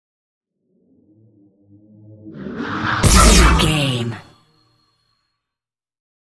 Sci fi shot whoosh to hit 760
Sound Effects
futuristic
intense
whoosh